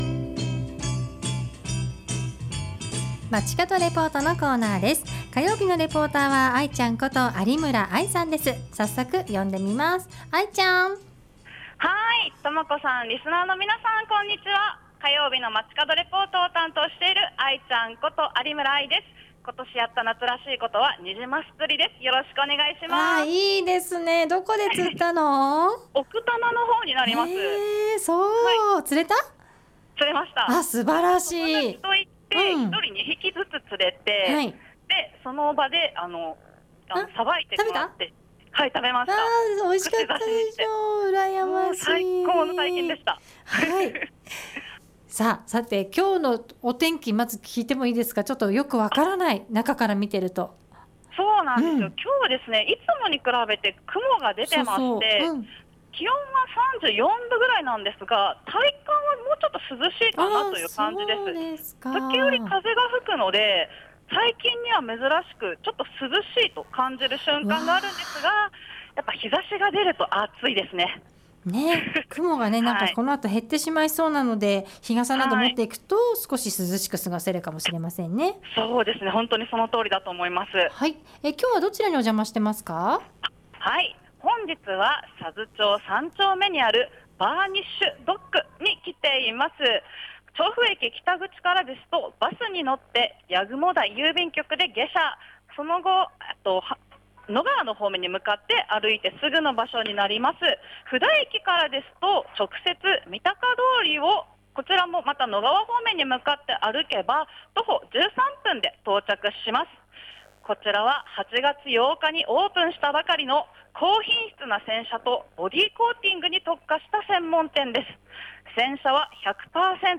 午後のカフェテラス 街角レポート
本日は、佐須町3丁目にある洗車とコーティング専門店、AZ BURNISCH DOCK(アーツェット バーニッシュ ドック)からお届けしました。